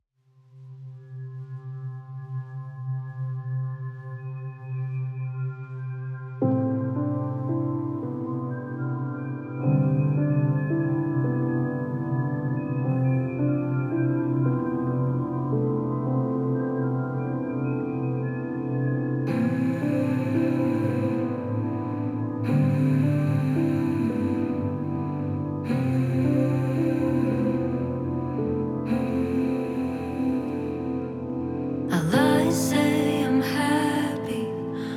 Indie Pop Alternative
Жанр: Поп музыка / Альтернатива